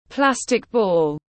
Quả bóng nhựa tiếng anh gọi là plastic ball, phiên âm tiếng anh đọc là /ˈplæs.tɪk bɔːl/
Plastic ball /ˈplæs.tɪk bɔːl/
Plastic-ball-.mp3